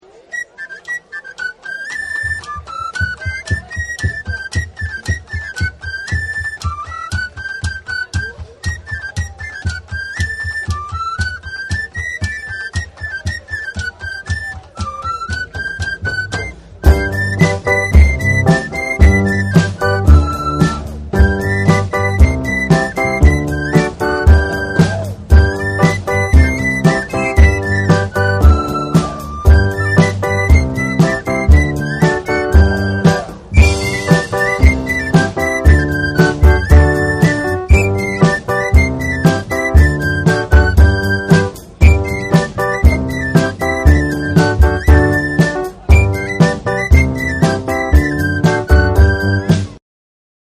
• Musiques de Balèti du Bal des Feux de la St Jean d’Aix :